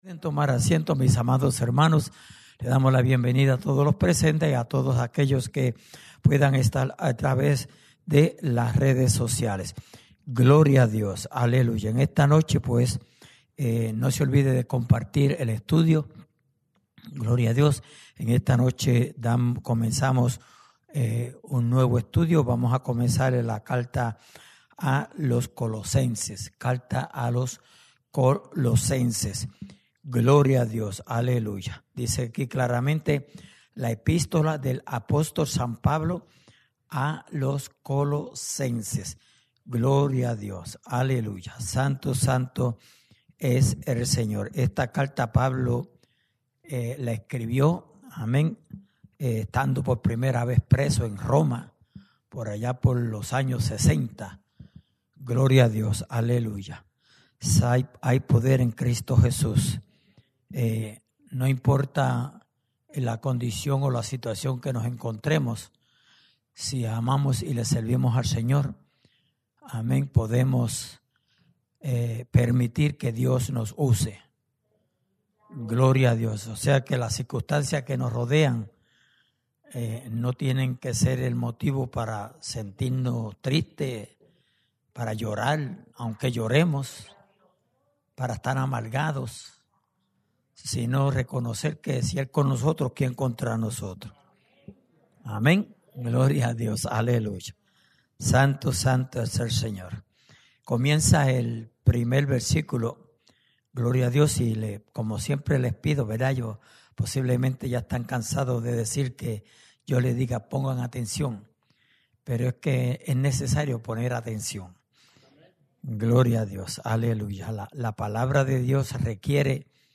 Estudio Bíblico: Libro de Colosenses